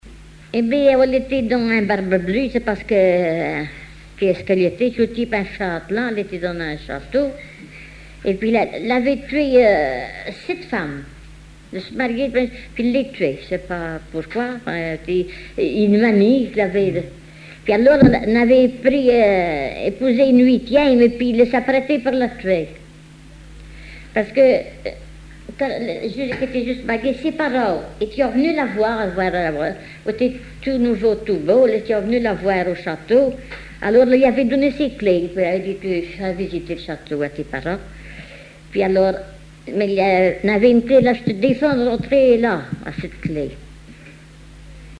Genre conte
Enquête La Soulère, La Roche-sur-Yon